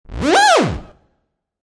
Download Theremin sound effect for free.
Theremin